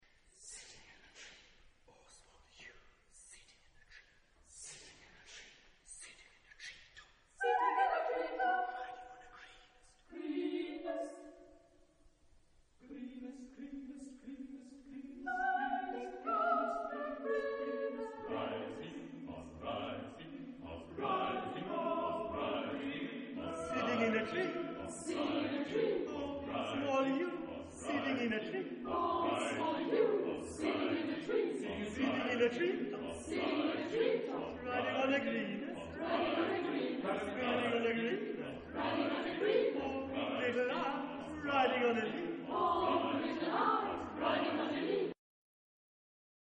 Zeitepoche: 20. Jh.
Chorgattung: SSAATTBB gemischter Chor
Solisten: Solo